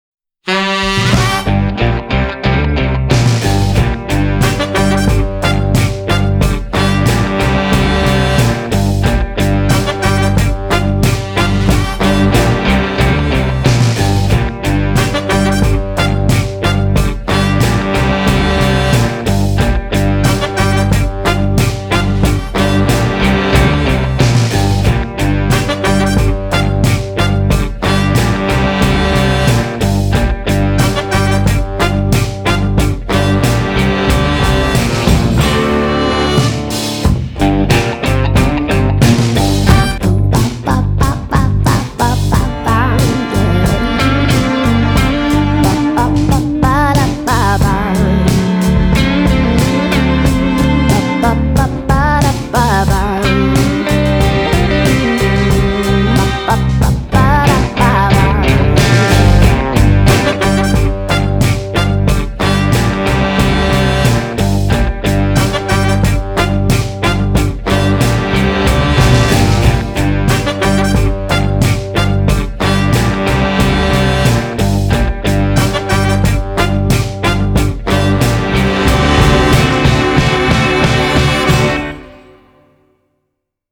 • Качество: 320, Stereo
красивые
женский вокал
без слов
инструментальные